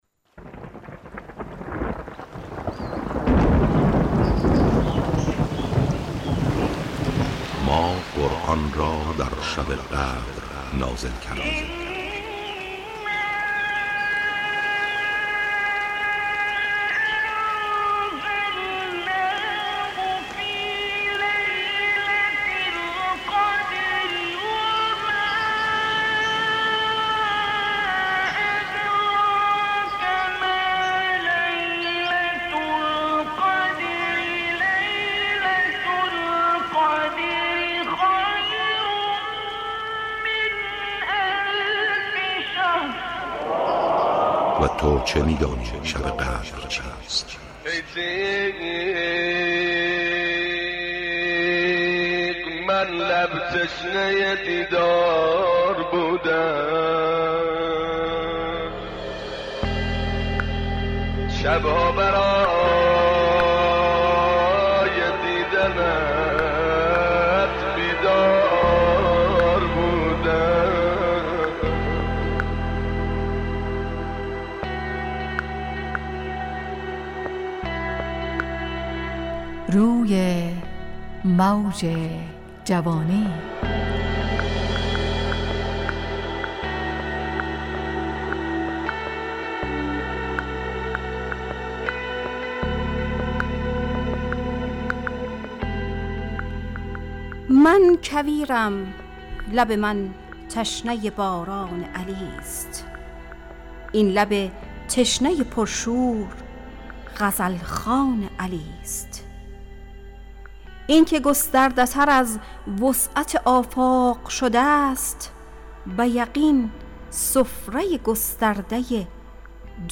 همراه با ترانه و موسیقی مدت برنامه 70 دقیقه .